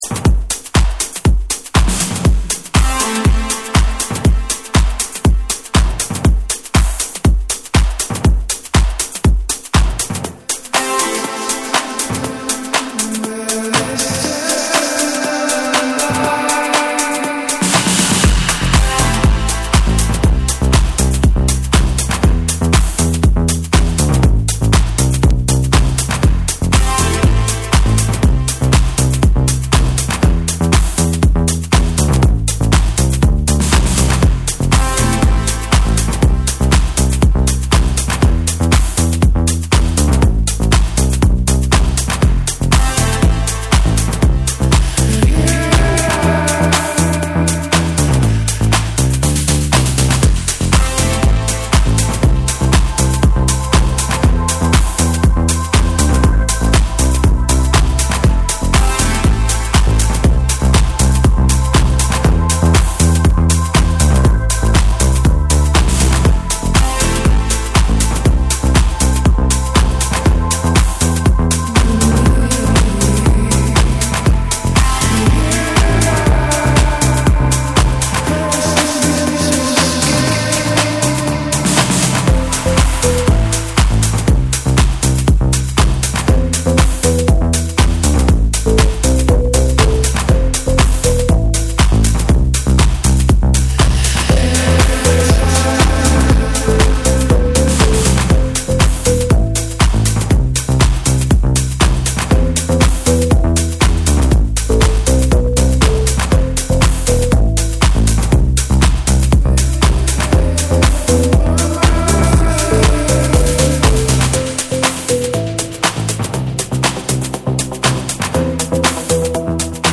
幻想的でドリーミーなシンセとディープなベースライン交わる3。
TECHNO & HOUSE